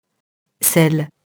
selle [sɛl]